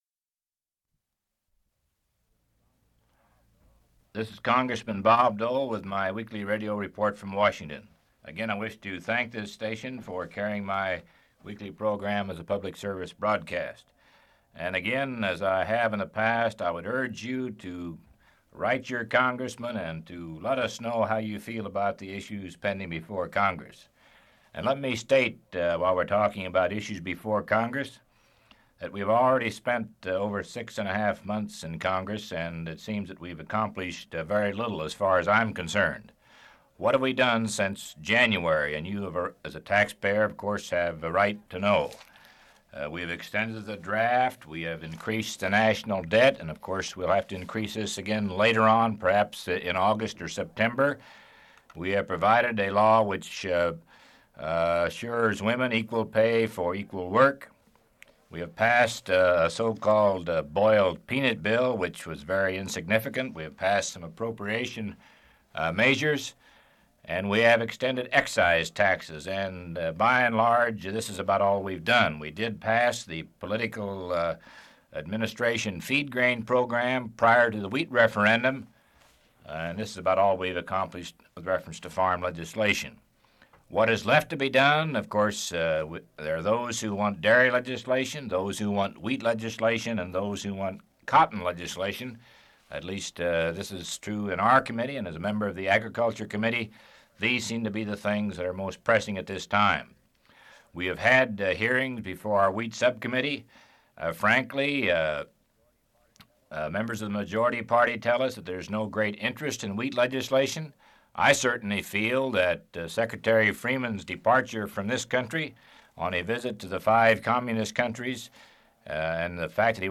Part of Weekly Radio Report: Civil Rights; Congressional Activities; & the Wheat Referendum